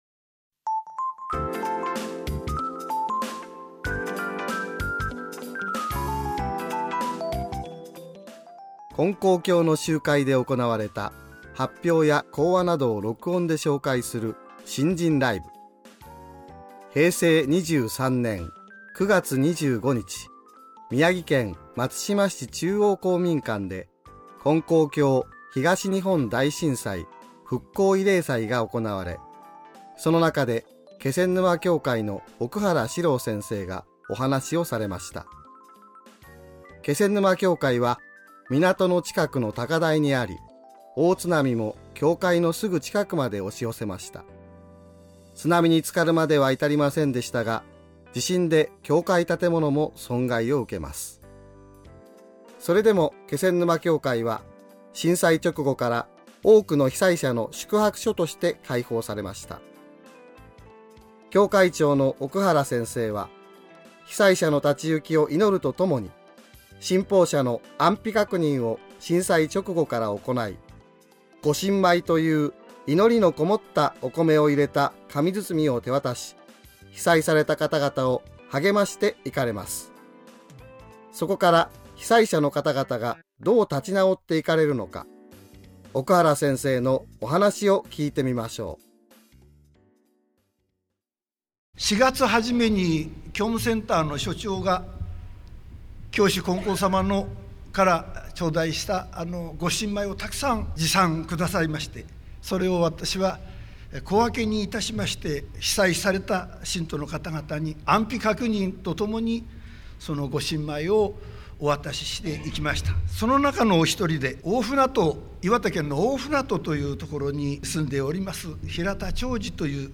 金光教の集会で行われた発表や講話などを録音で紹介する「信心ライブ」。